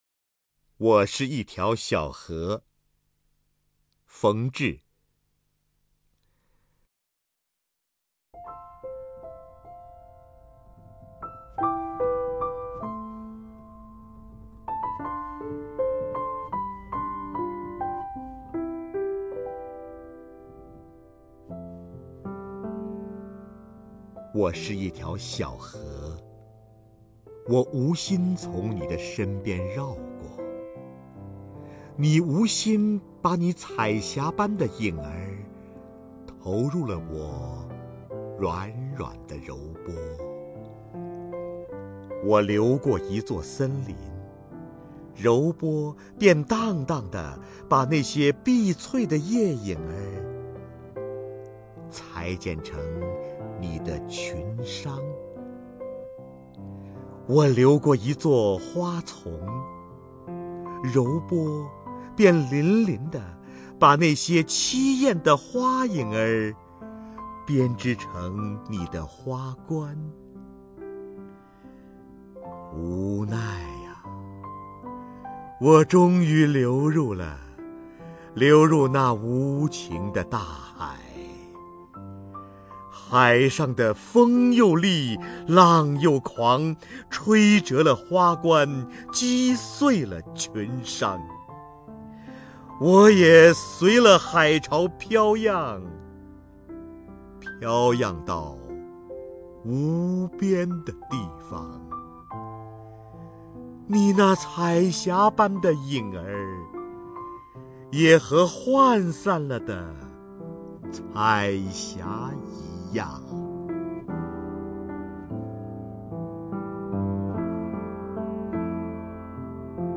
首页 视听 名家朗诵欣赏 瞿弦和
瞿弦和朗诵：《我是一条小河》(冯至)